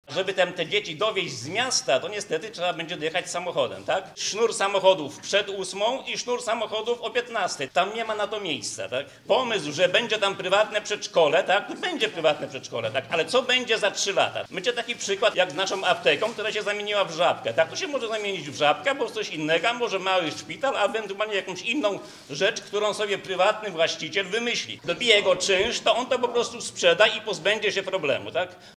– Chcemy spokoju i niezakorkowanych ulic – mówili mieszkańcy osiedla Piaski.